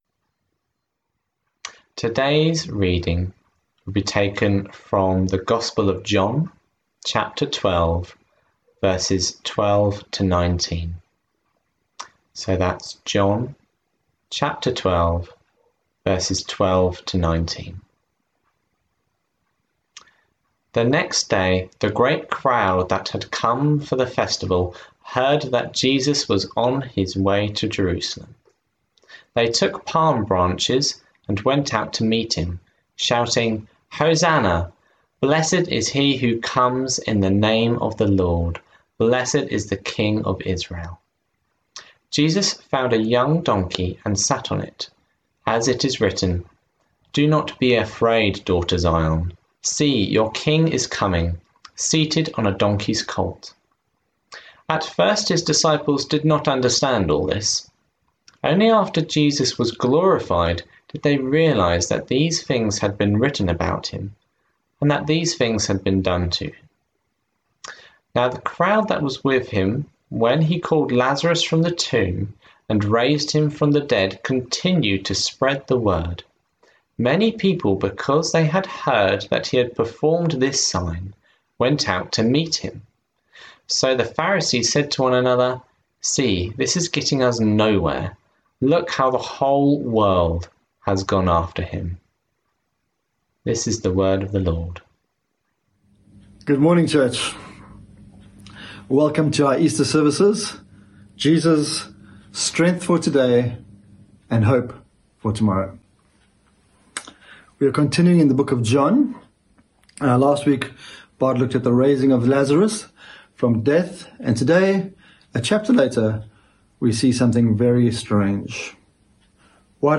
John 12:12-19 Service Type: Streaming Text